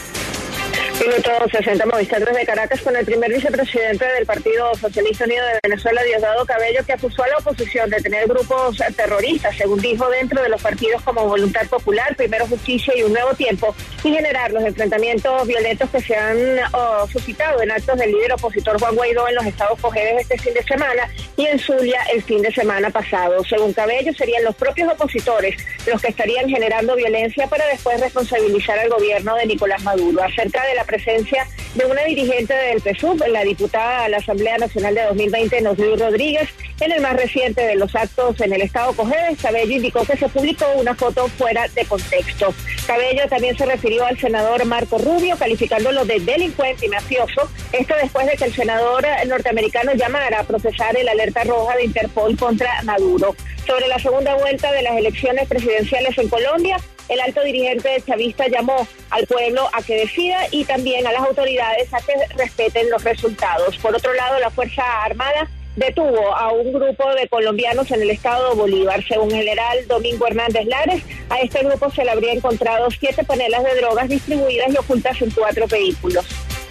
En una conferencia de prensa del Partido Socialista Unido de Venezuela, el número dos del chavismo, Diosdado Cabello, acusó a Rubio de ser “un delincuente”.